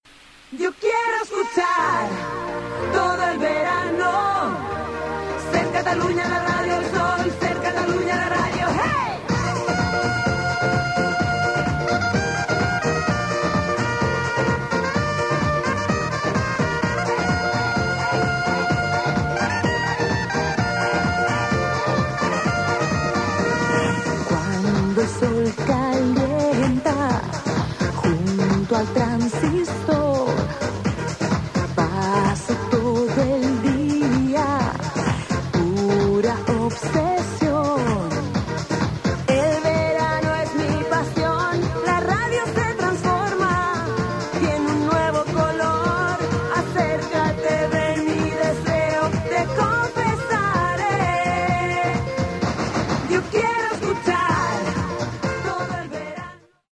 Cançó identificativa del programa